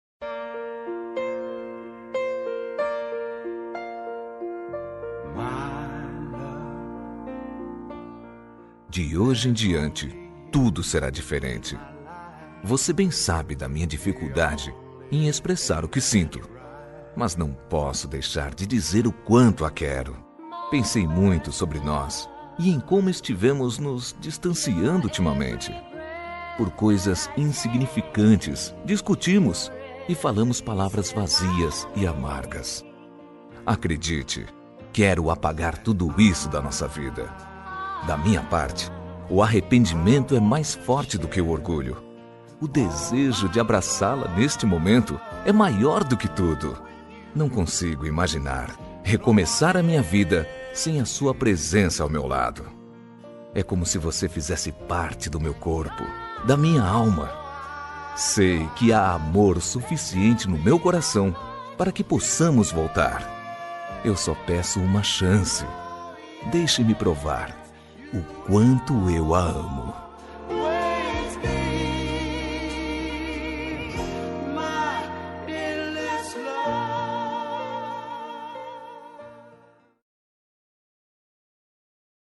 Reconciliação – Voz Masculina – Cód: 02651 – Linda
02651-reconcil-masc-linda.m4a